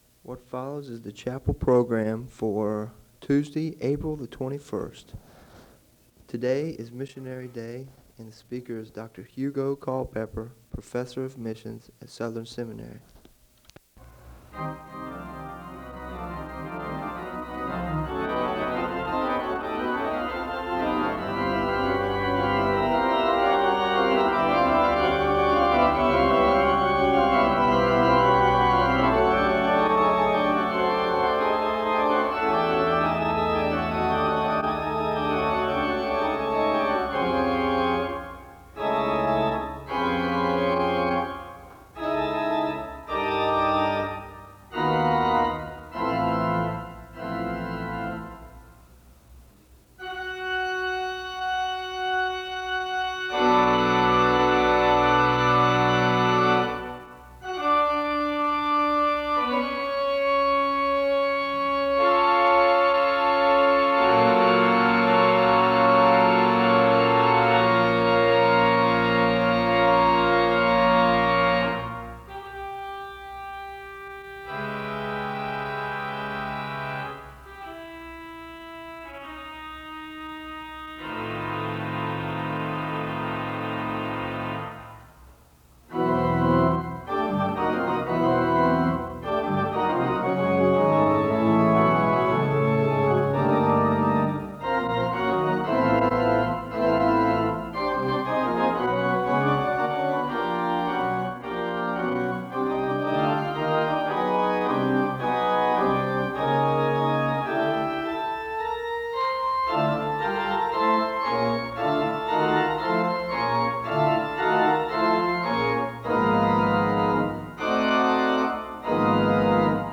The service begins with organ music and a word of prayer (00:00-09:22).
The choir sings a song of worship (13:48-17:12).
The service ends with organ music and a benediction (57:25-58:16).